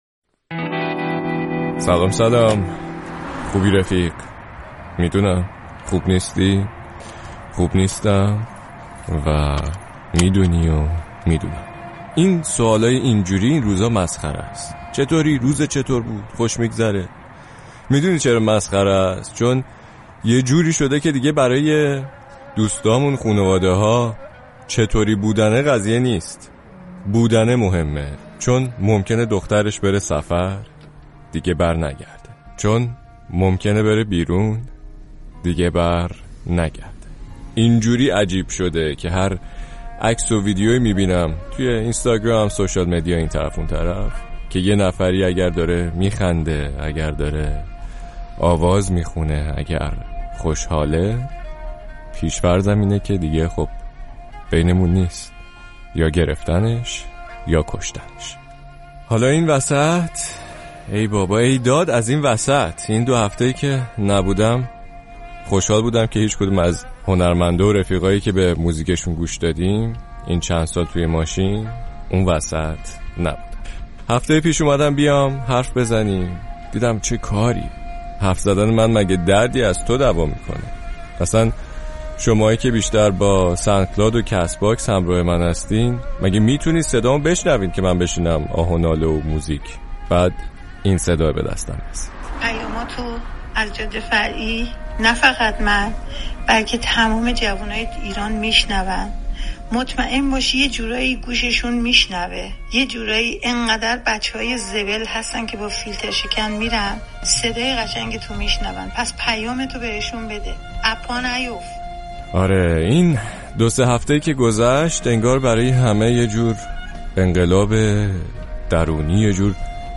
موسیقی بدون کلام استفاده شده